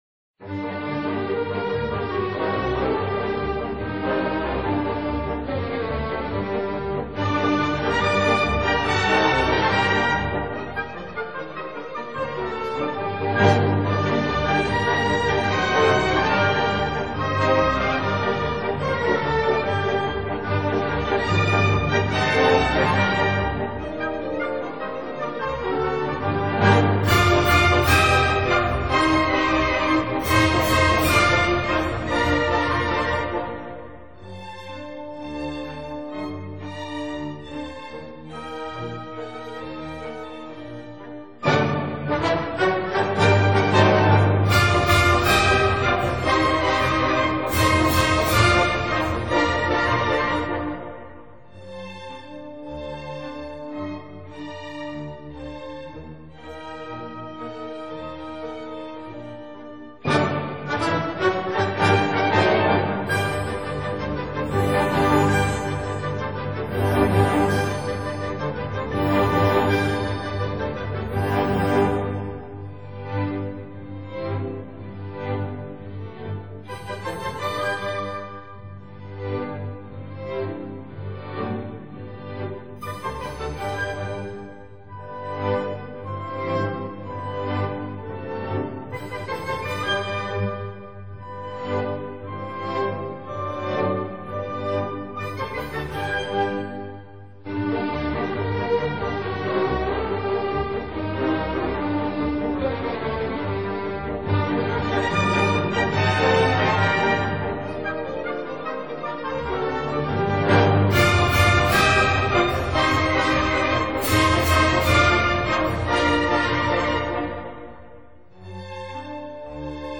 大家熟悉的、帶著哀怨味道的旋律，在原來的聯彈曲中，是由Se-condo聲部(即低音部)彈出來。
此曲蘊藏若一股哀愁與熱情，充滿著力感與朝氣。